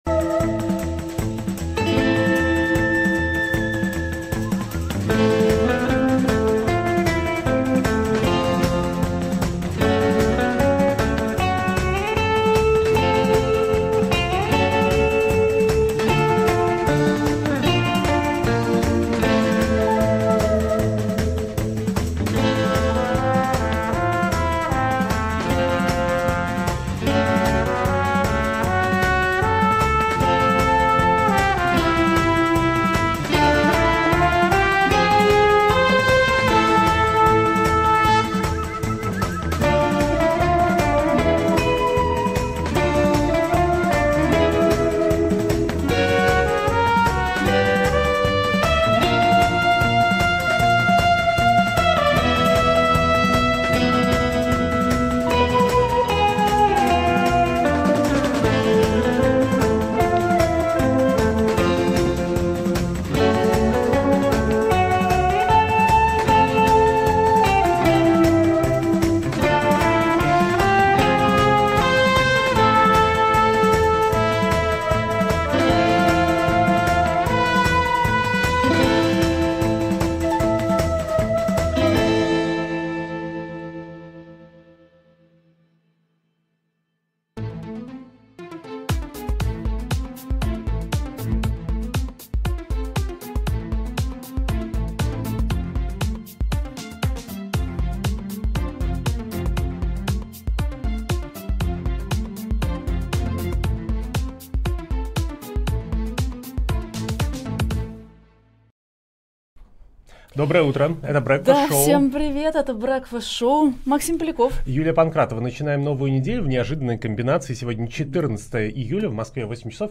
обсудят с экспертами в прямом эфире The Breakfast Show все главные новости. Что происходит с наступлением российской армии в Украине? Насколько велика роль северокорейской помощи в войне?